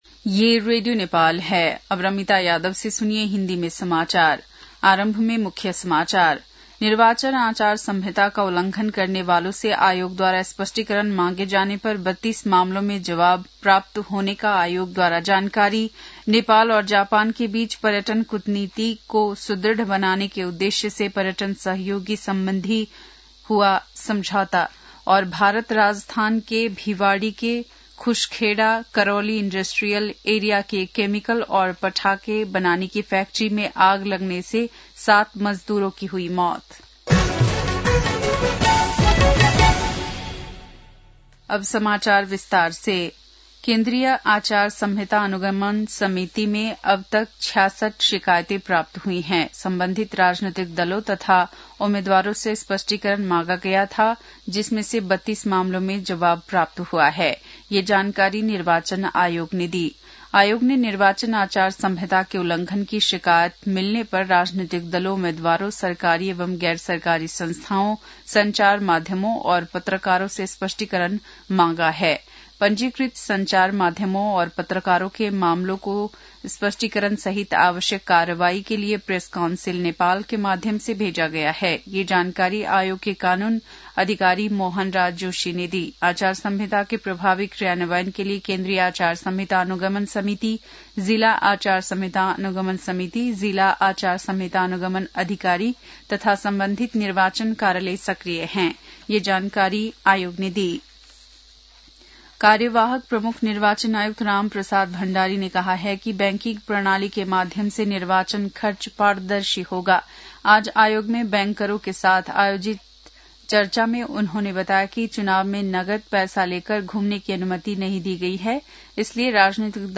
बेलुकी १० बजेको हिन्दी समाचार : ४ फागुन , २०८२